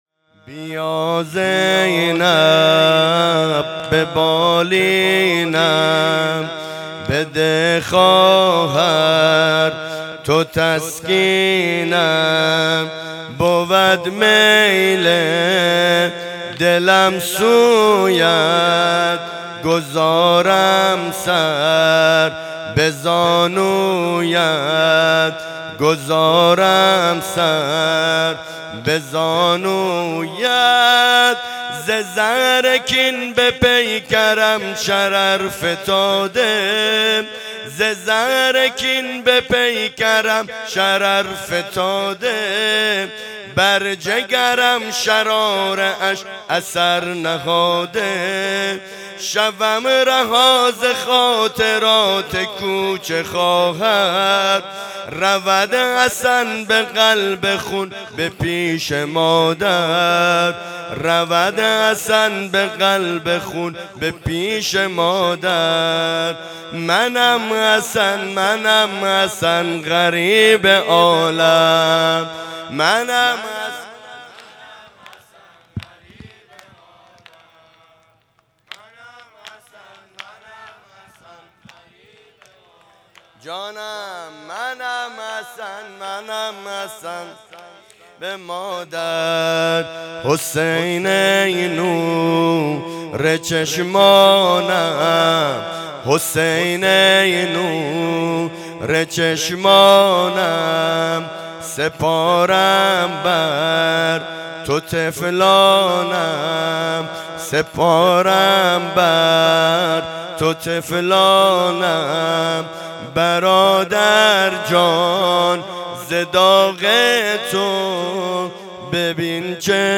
مداحی سنتی یزد